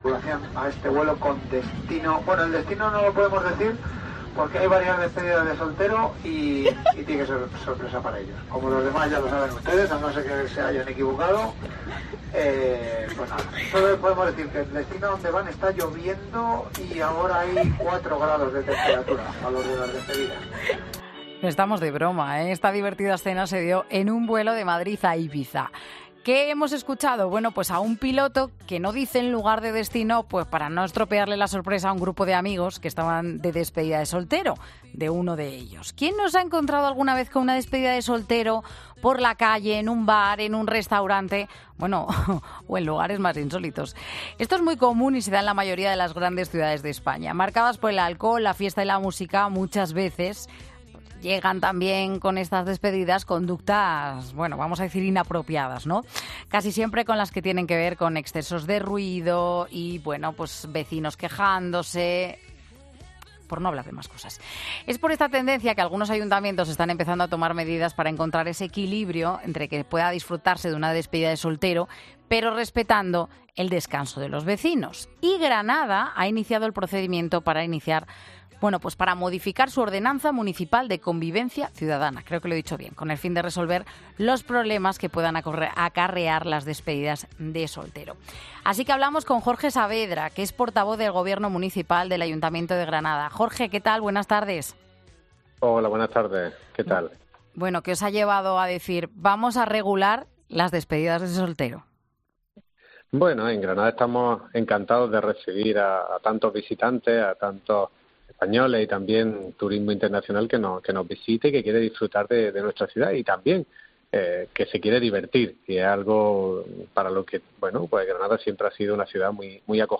Precisamente por eso, el Ayuntamiento de Granada ha decidido modificar ahora su ordenanza para garantizar la convivencia entre los visitantes y los vecinos. Aquí tienes el audio en el que Jorge Saavedra, portavoz del Gobierno municipal del Ayuntamiento de Granada, explica por qué te pueden multar en 'Mediodía COPE'.